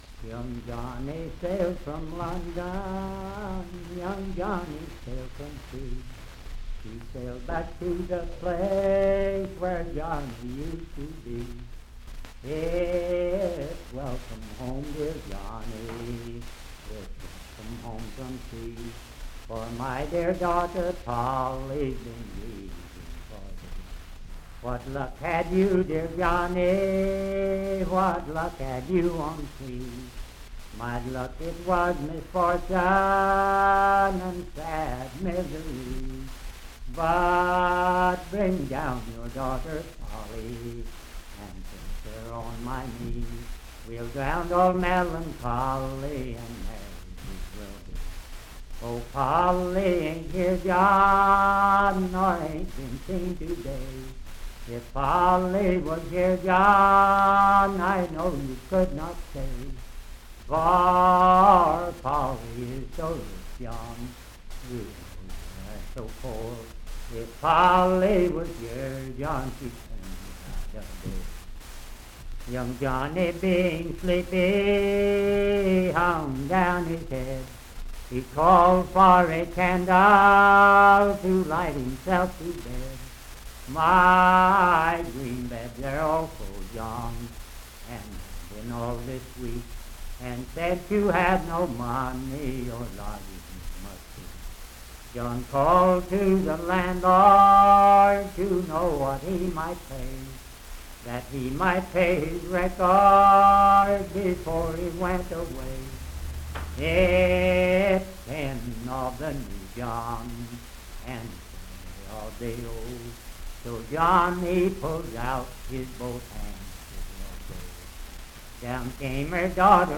Unaccompanied vocal music and folktales
Voice (sung)
Parkersburg (W. Va.), Wood County (W. Va.)